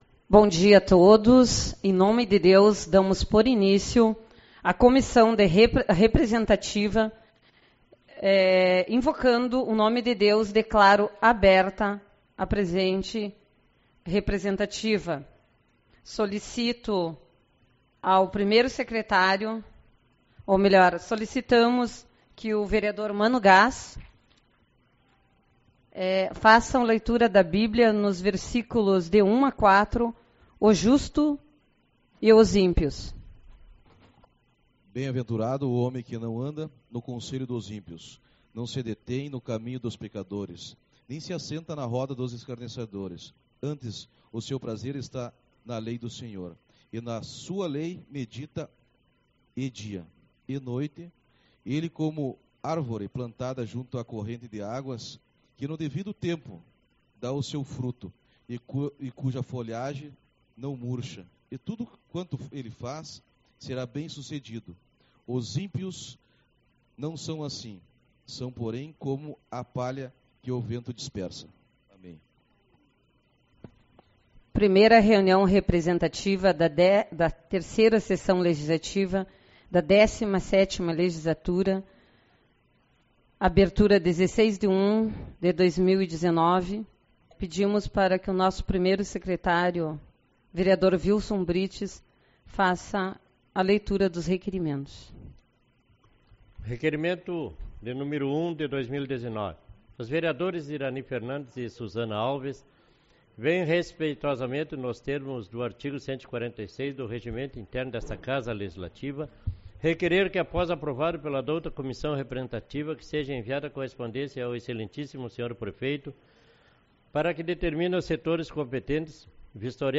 16/01 - Reunião Representativa